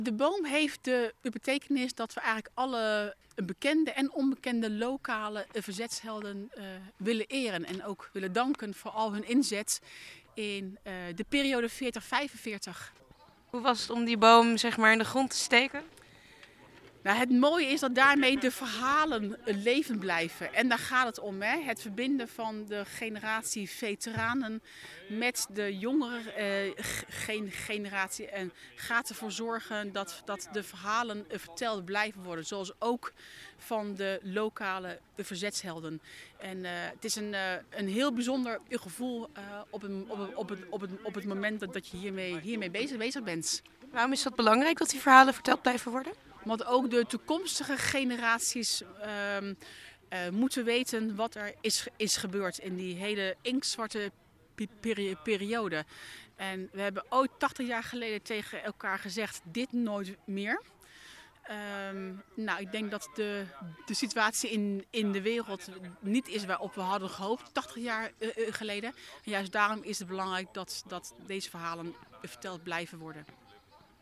in gesprek met burgemeester Nadine Stemerdink